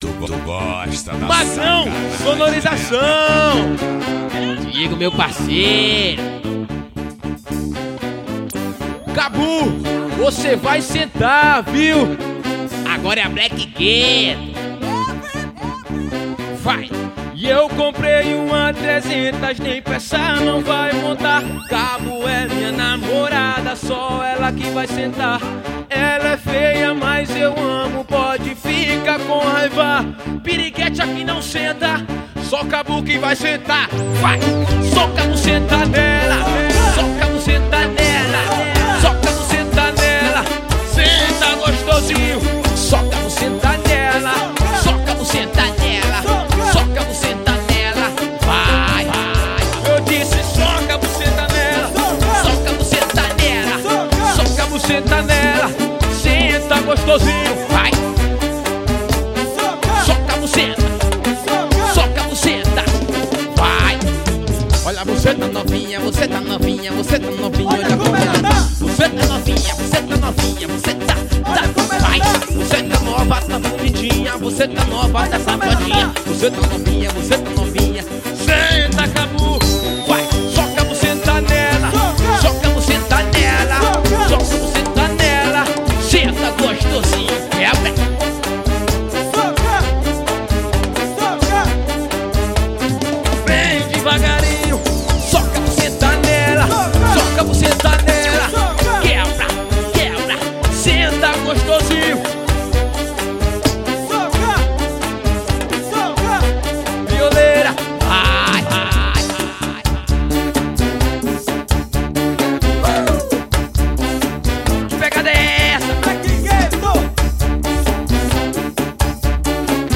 PAGODÃO.